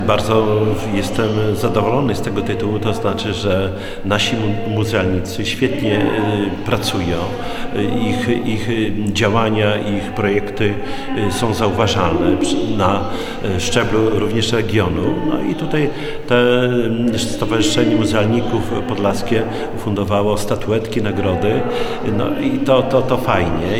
Jak mówi Czesław Renkiewicz, prezydent Suwałk, nagrody to znak, że suwalscy muzealnicy ciężko pracują.